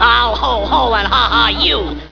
hohohaha.wav